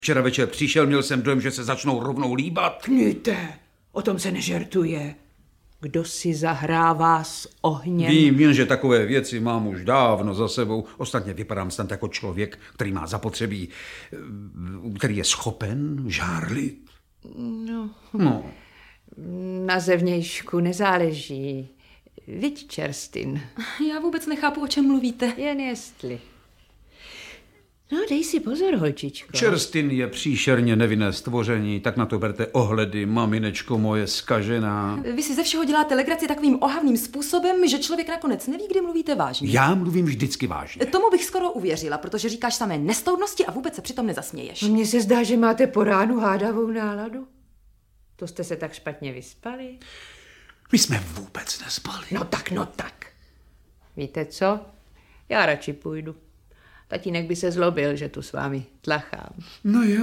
Audiobook
Read: Naďa Konvalinková